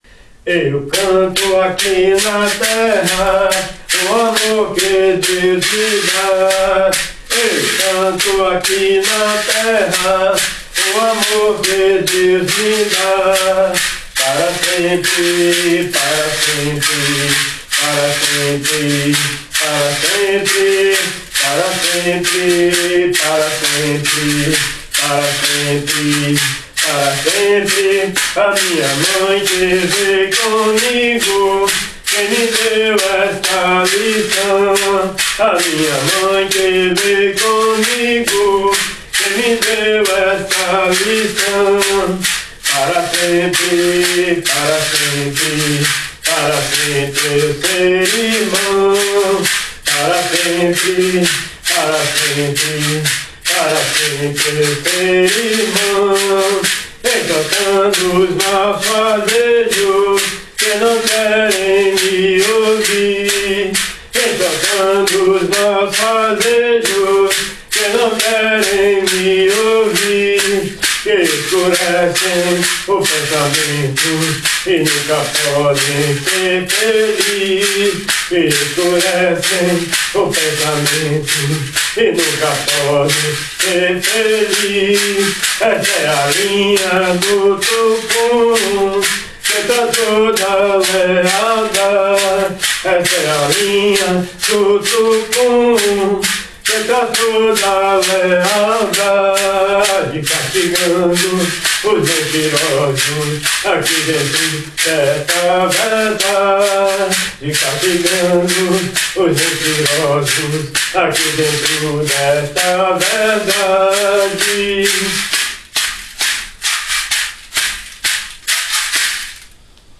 Hymne 108 Linha do Tucum von Hymnarium O Cruzeiro